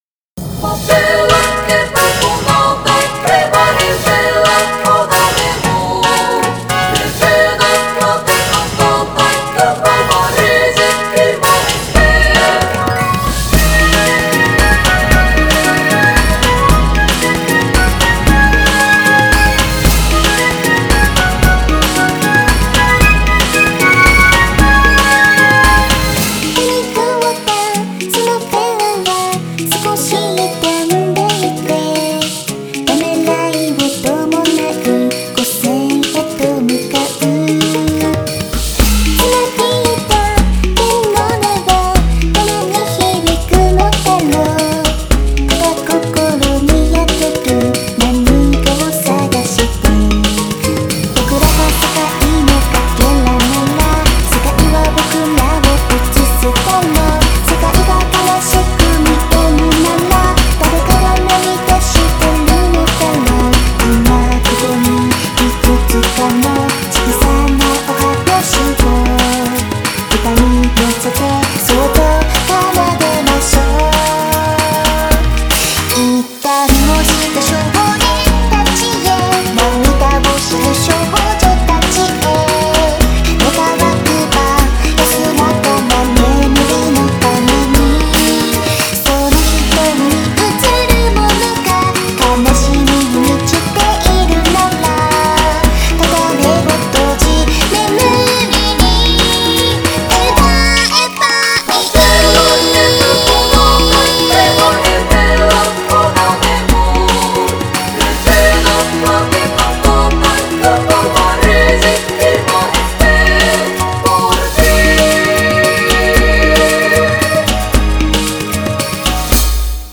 BPM114
Audio QualityPerfect (High Quality)
Genre: LA FABELO.